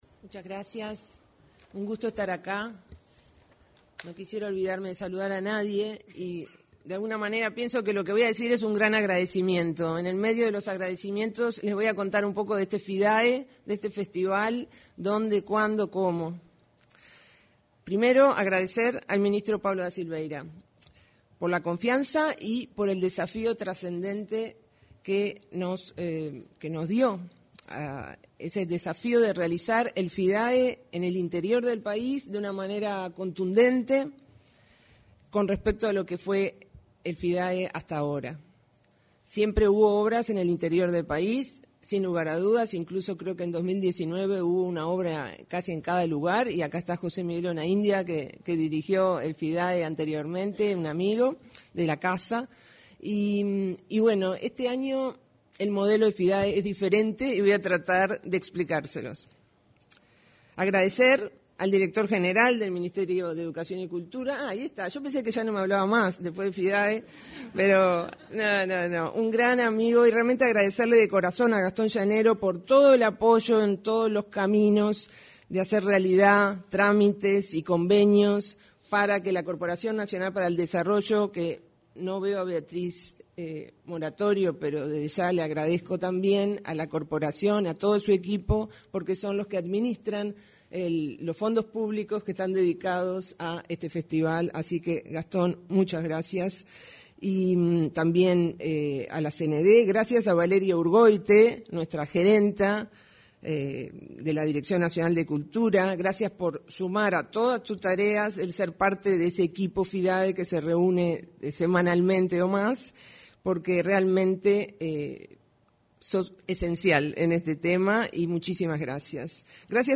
Palabras de la directora nacional de Cultura, Mariana Wainstein
El Ministerio de Educación y Cultura (MEC) realizó, este 12 de junio, el lanzamiento del Festival Internacional de Artes Escénicas.